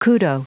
Prononciation du mot kudo en anglais (fichier audio)
Prononciation du mot : kudo